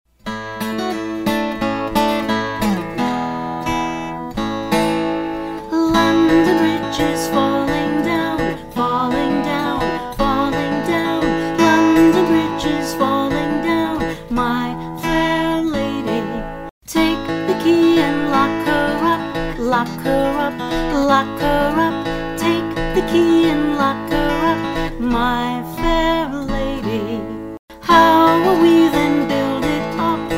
Downloadable Song with Lyrics